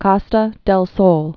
(kŏstə dĕl sōl, kôstə, kō-, kōsthĕl)